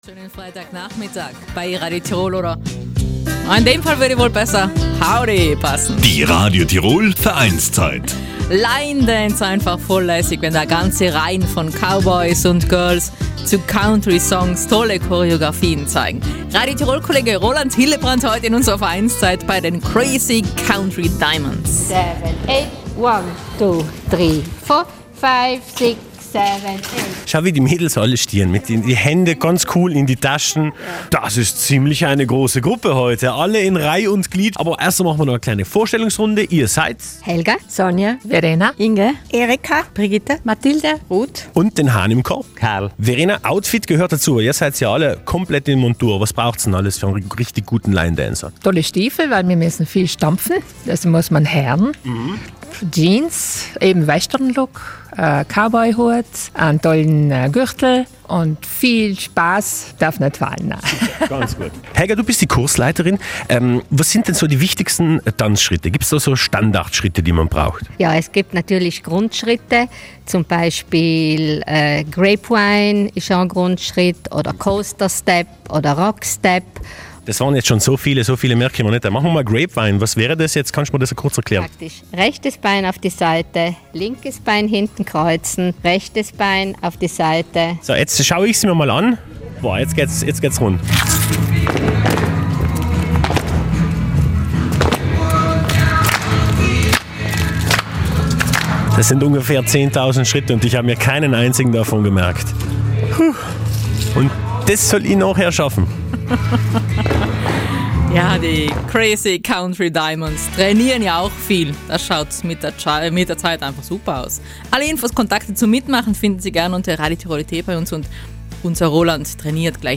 Im Gegensatz zum Paartanz stehen die Line Dancer in Reihen (Lines) neben oder hintereinander und tanzen zu Western- und Countrymusik. Die ca. 100 Line Dancer von den Crazy Country Diamonds klatschen, stampfen und drehen sich mit viel Begeisterung.